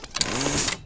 mine.deploy.ogg